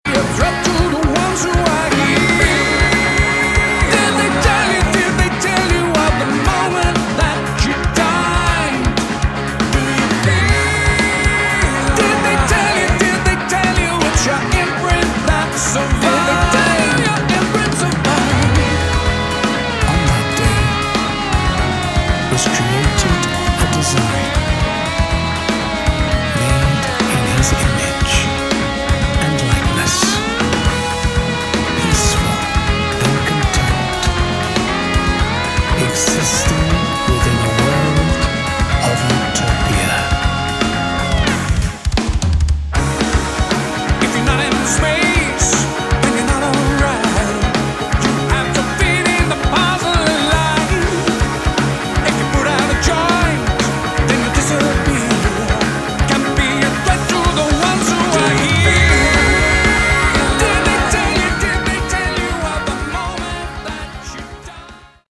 Category: Prog Rock
vocals
guitars, bass
drums
keyboards, Hammond organ, piano
Nice retro prog rock.